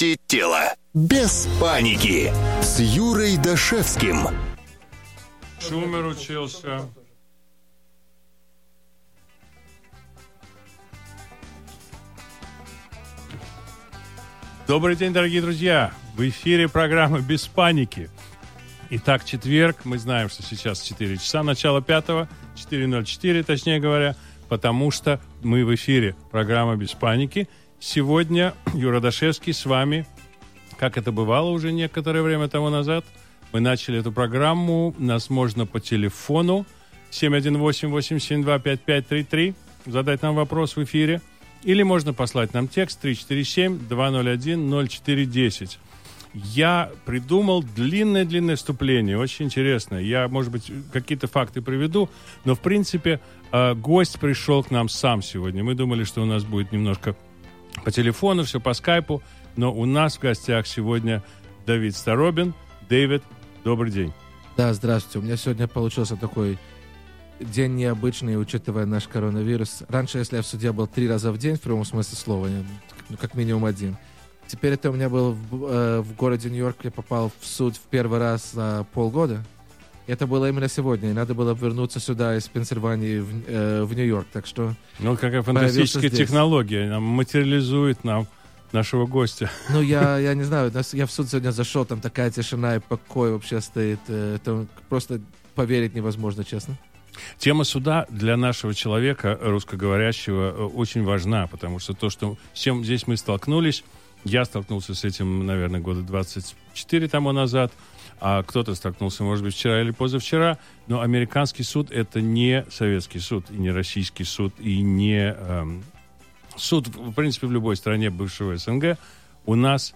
Ток-шоу "Без паники!"